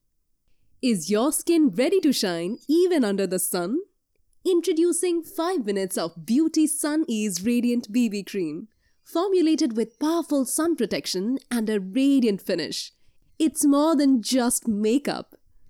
Voice over and Dubbing Artist
indian english voice | Advertisement Voice